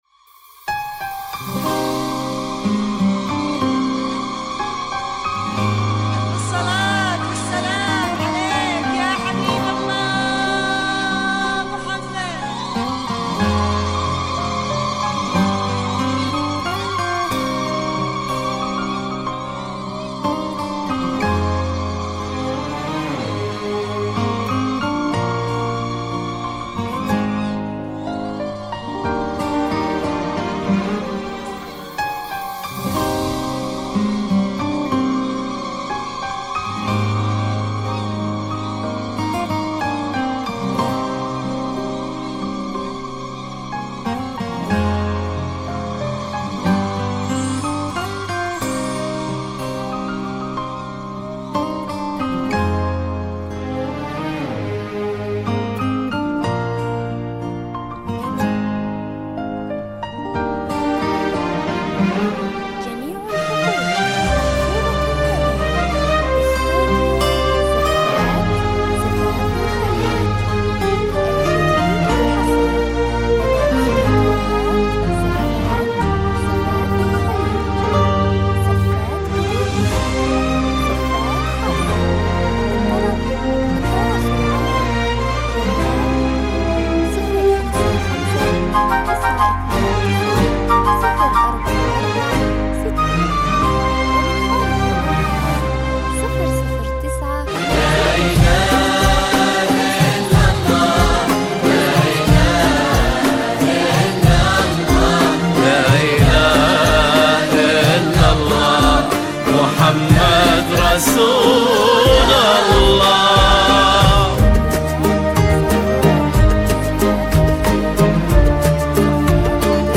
موسيقى ( بدون كلام )